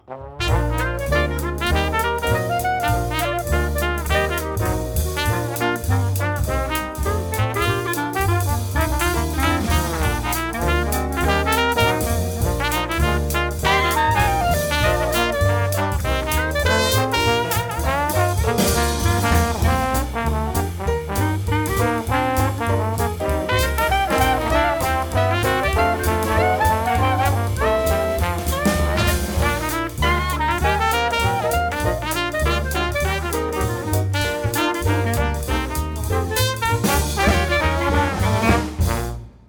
• Instrumentalt
Band
Stilfuldt julejazzshow med klassiske sange.
Elegant udtryk og rutineret orkester